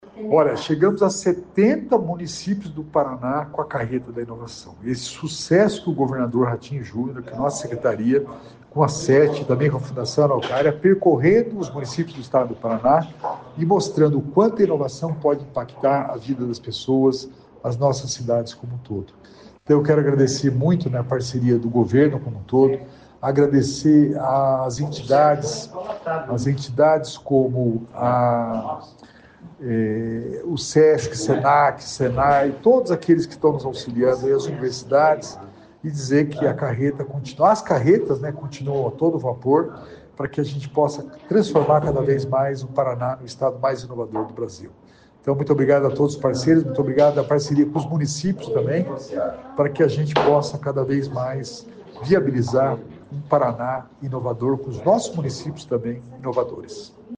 Sonora do secretário Estadual da Inovação e Inteligência Artificial, Alex Canziani, sobre as Carretas da Inovação em 2025